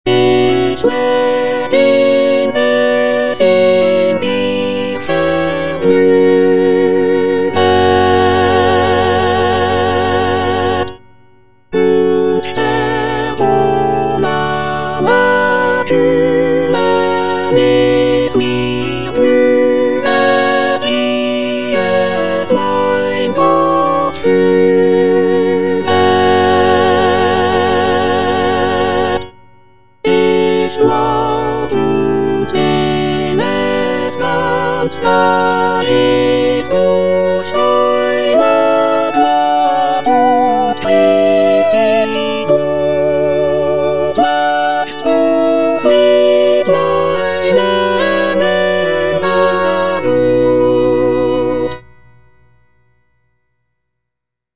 The work features a joyful and optimistic tone, with the solo soprano expressing gratitude for the blessings in her life. The text explores themes of contentment, trust in God, and the acceptance of one's fate.